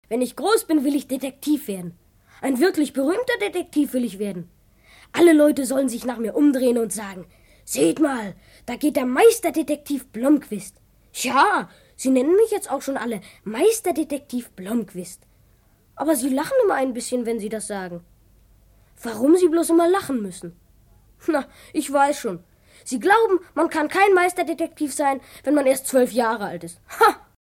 Schlagworte Abenteuer • Audio-CD • AUDIO/Kinder- und Jugendbücher/Kinderbücher bis 11 Jahre • Detektiv • Detektive • Detektivgeschichte • ermitteln • Freundschaft • Hörbücher • Hörbuch für Kinder/Jugendliche • Hörbuch für Kinder/Jugendliche (Audio-CD) • Kinderbande • Kinder-CDs (Audio) • Klassiker • Kleinköping • Krimi • Nostalgie • Schweden • Skandinavien • spannend • Spannung • Tonträger